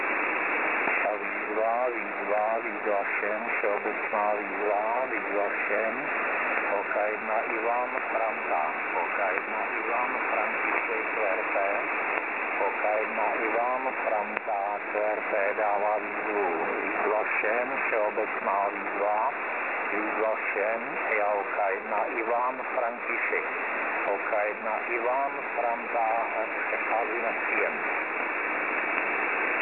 Vzal jsem sebou uSDX na setkání HAMS ve Starých Splavech. Nakonec jsem ho však testoval jen v RX módu.
uSDX LSB pres OK1KPU SDR.mp3
Nahrávky jsou provedeny cca v 10UTC.
uSDX_LSB.mp3